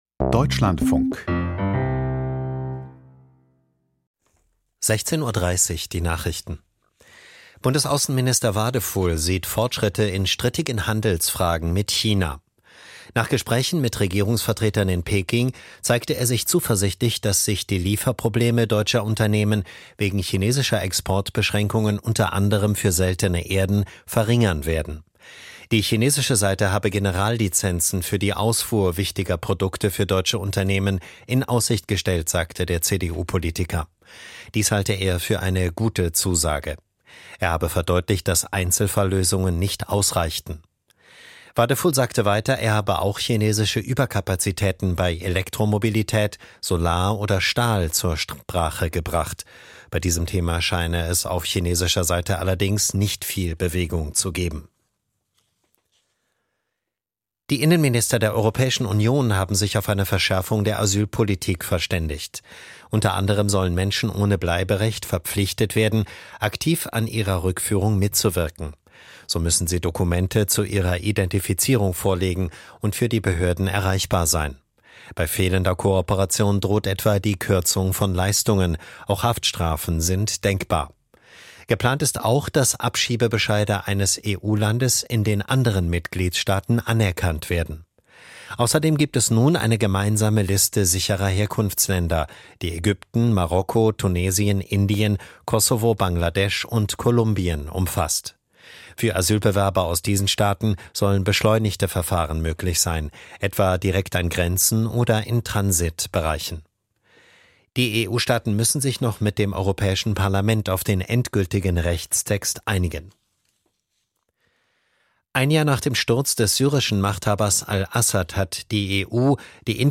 Die Nachrichten vom 08.12.2025, 16:30 Uhr